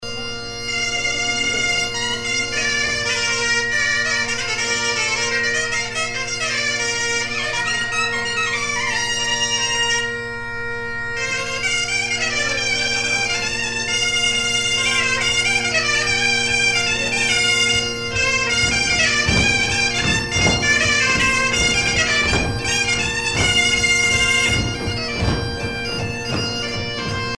danse : fisel (bretagne)
Pièce musicale éditée